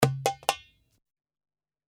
130 BPM malfuf darbuka mono (28 variations)
The darbuka is already with eq and dynamics, so all you have to do is to put it in a track, Add some room reverb and adjust the volume.
This darbuka track has 28 variations in malfuf style.
The darbuka is in 130 bpm, mono and dry.